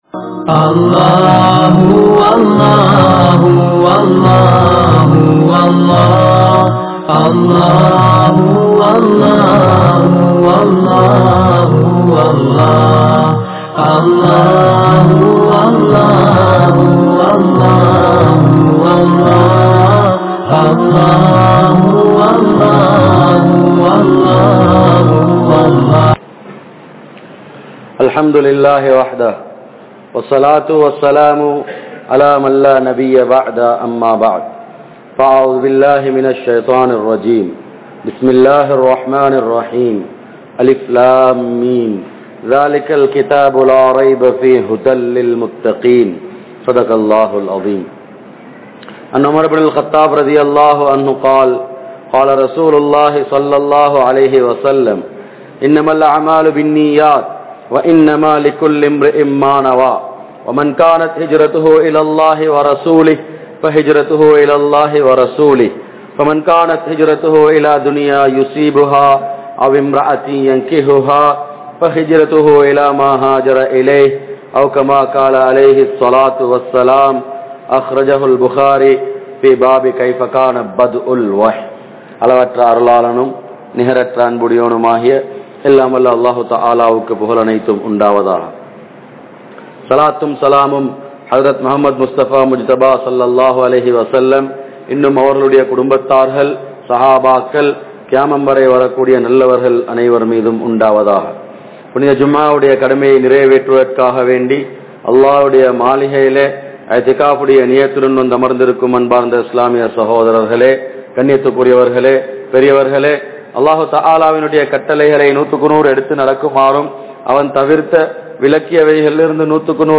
Petroarhal Kulanthaihaludan Palahum Murai (பெற்றோர்கள் குழந்தைகளுடன் பழகும் முறை) | Audio Bayans | All Ceylon Muslim Youth Community | Addalaichenai
Majmaulkareeb Jumuah Masjith